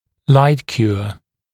[laɪt-kjuə][лайт-кйуэ]светоотверждаемый